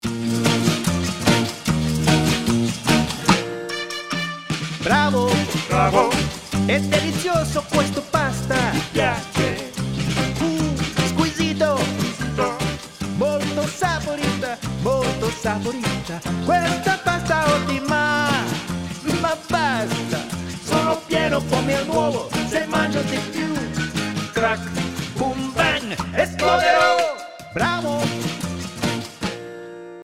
Italian